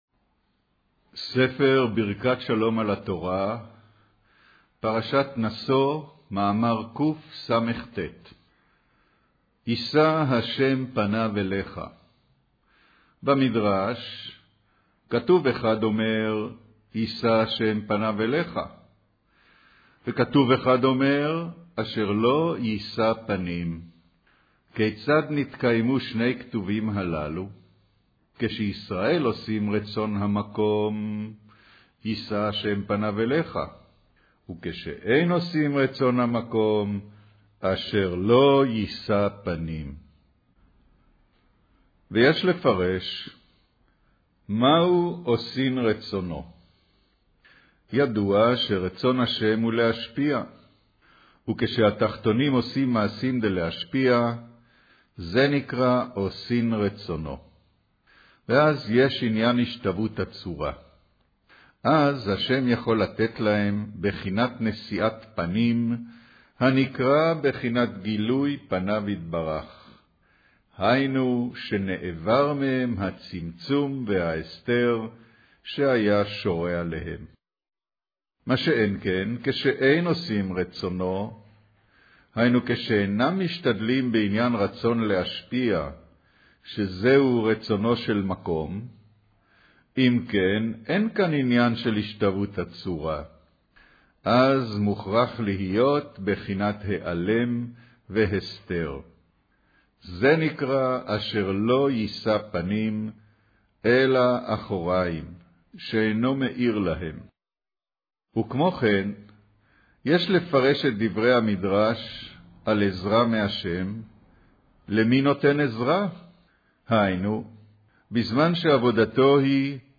אודיו - קריינות